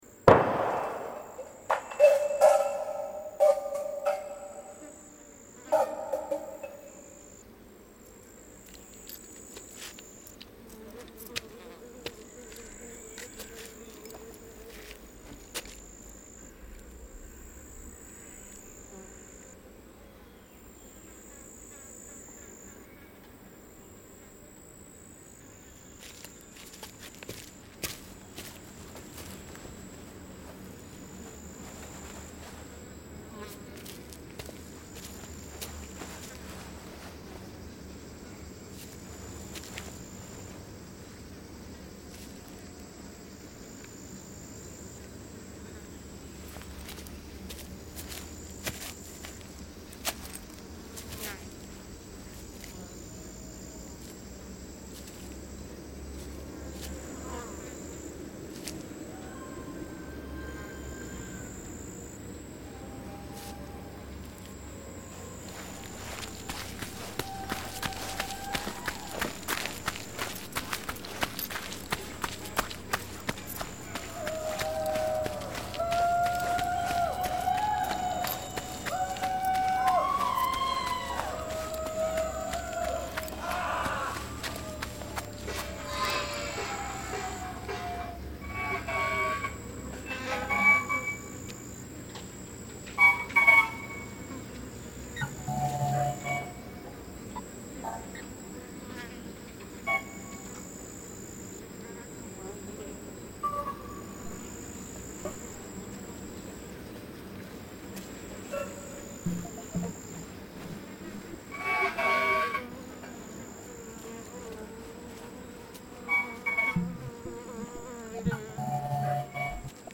Californian lockdown sound reimagined